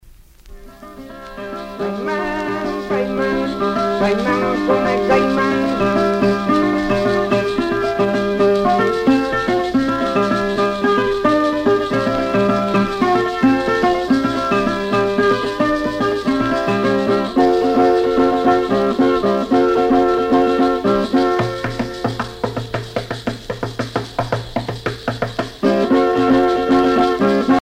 Pièces musicales tirées de la Parranda Tipica Espirituana, Sancti Spiritus, Cuba
Catégorie Pièce musicale inédite